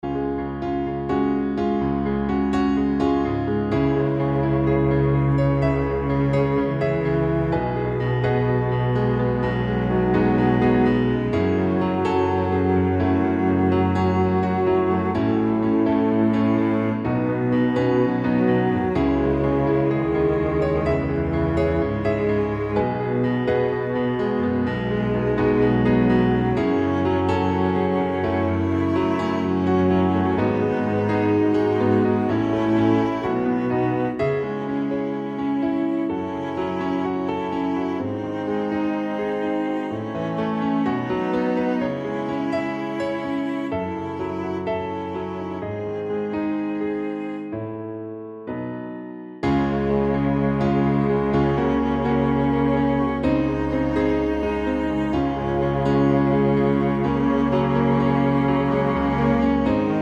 Up 3 Semitones For Female